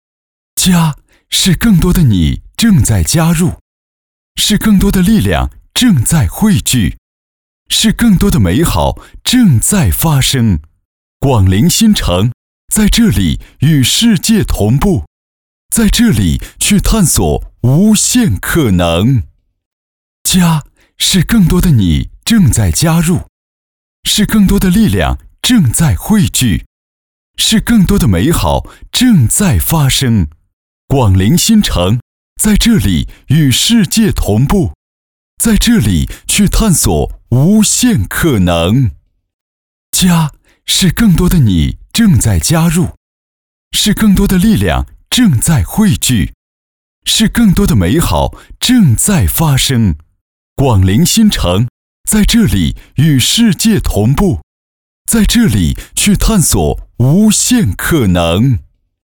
国语青年大气浑厚磁性 、沉稳 、男专题片 、宣传片 、40元/分钟男1 国语 男声 宣传片 【大气力度】郑州城市宣传片 大气浑厚磁性|沉稳